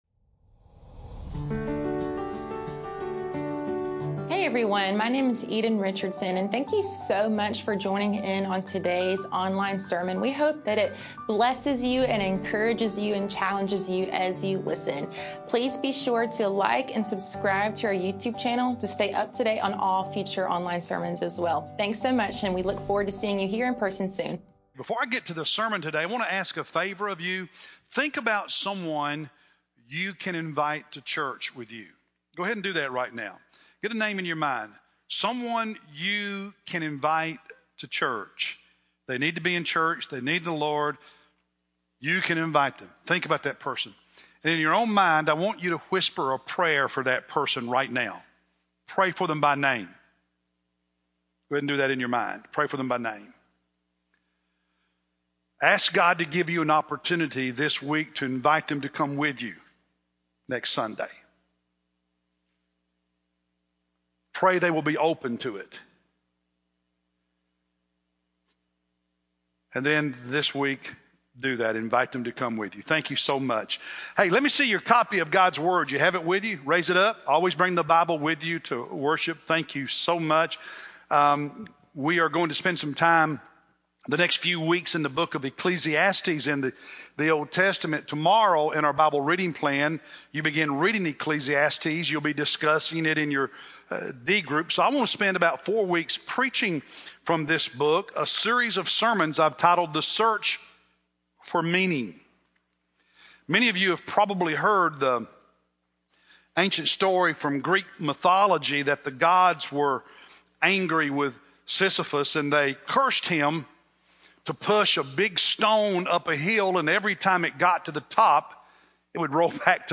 Oct-20-Sermon-CD.mp3